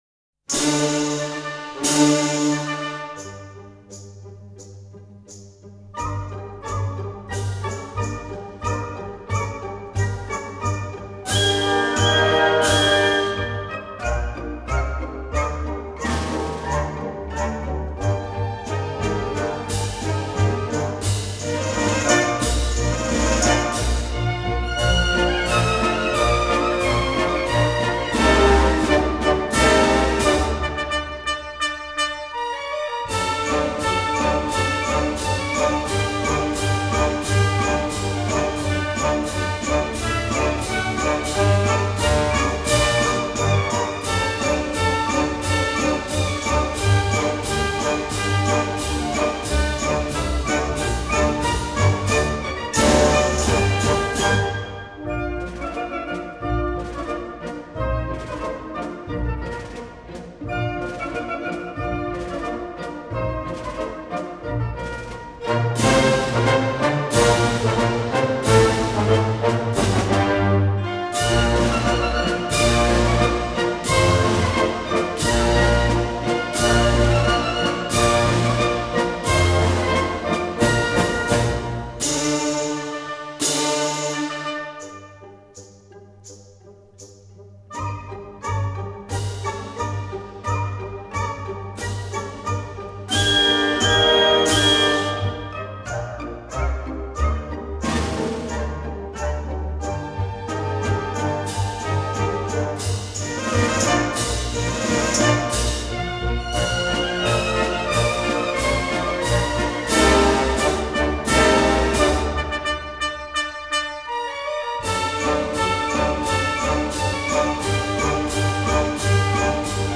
进行曲的特点是节奏清晰，强弱分明，节拍规正且常用4/4或4/2拍子；
结构多是均衡对称方正型的；旋律多是雄壮有力、刚健豪迈的，
其主题常采用大主和弦的分解进行为开端以造成昂扬向上的气势。